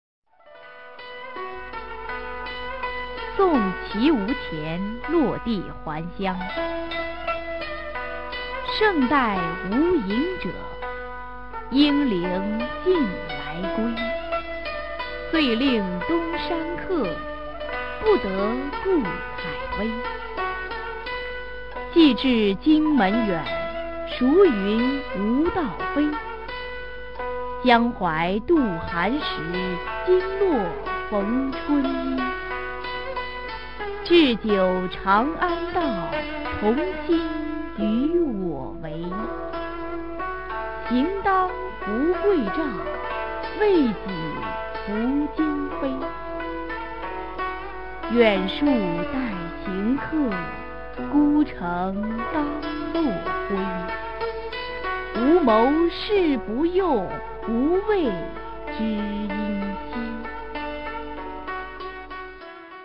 [隋唐诗词诵读]王维-送綦毋潜落第还乡a 配乐诗朗诵